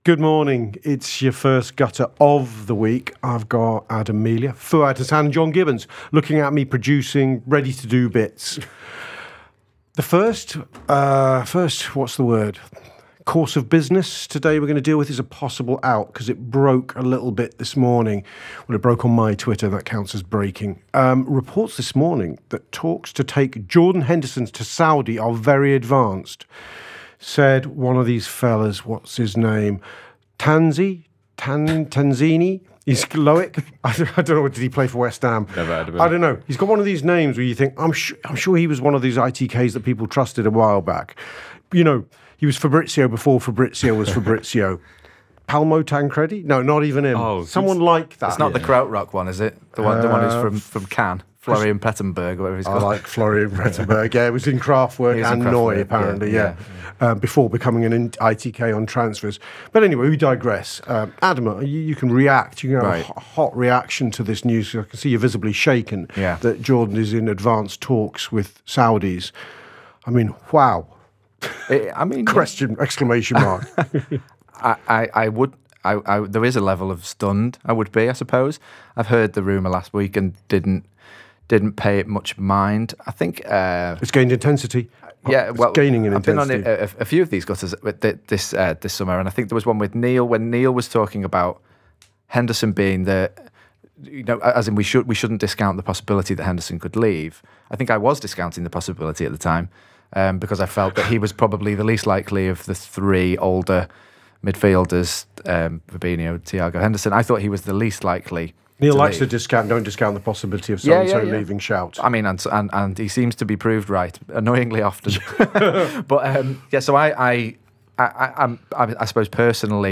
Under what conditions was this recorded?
Below is a clip from the show – subscribe for more on Marc Guehi and the rest of Liverpool’s links…